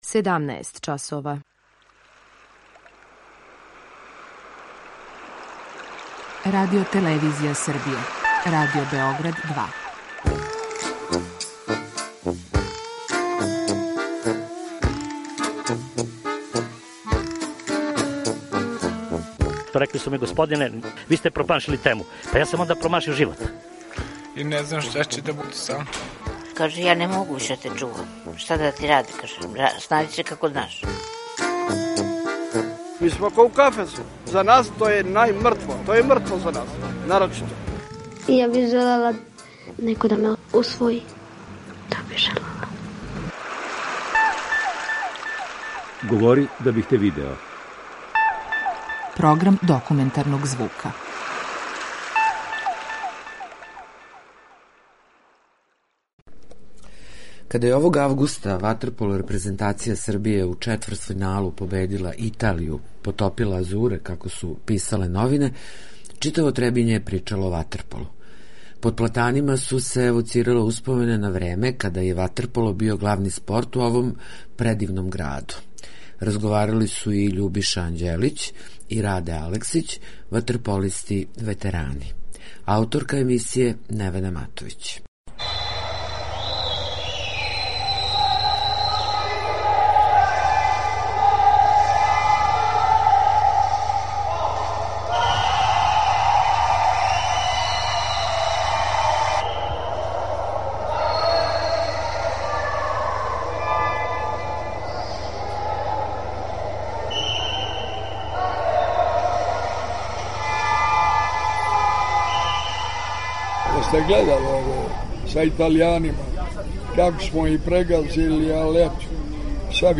Документарни програм (реприза емисије)